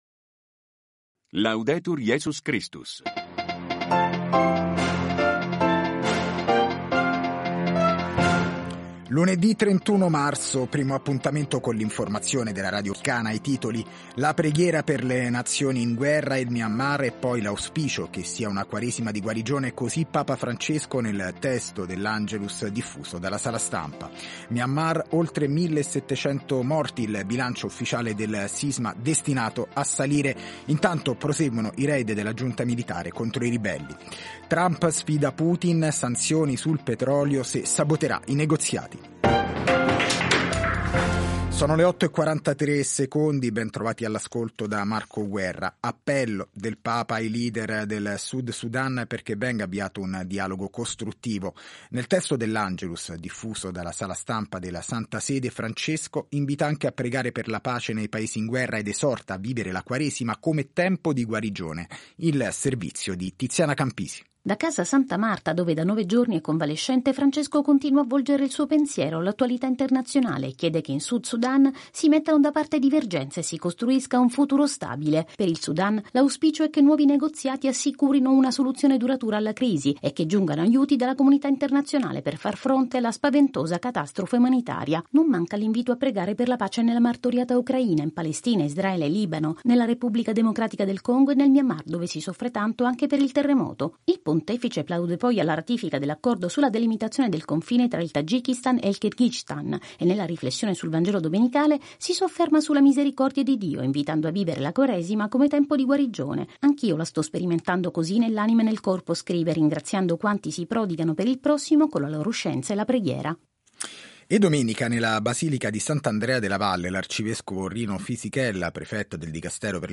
Notizie